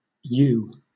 Ääntäminen
Southern England
IPA : /juː/